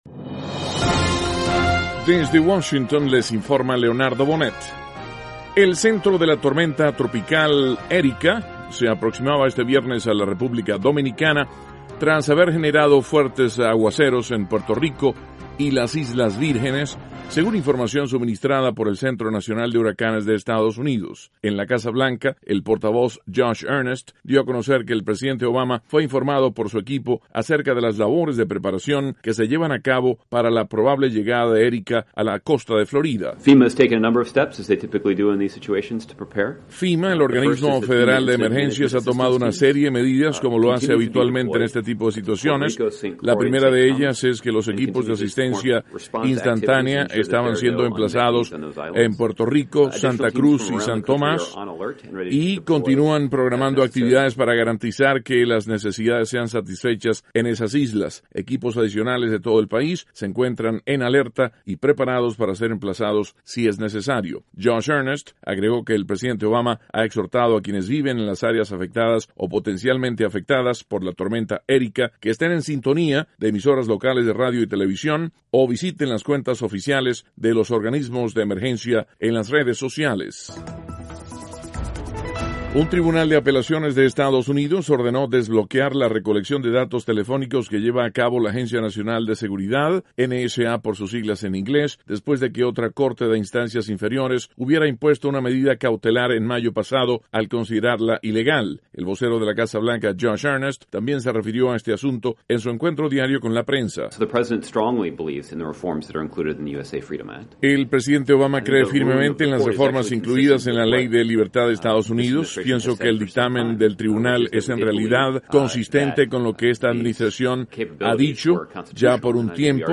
En su encuentro diario con la prensa, el vocero de la Casa Blanca habló de: La Tormenta tropical Erika; De la decisión de la Corte de Apelaciones de EEUUde rechazar una medida que habría limitado la recolección de metadatos telefónicos por parte de la Agencia de Seguridad Nacional.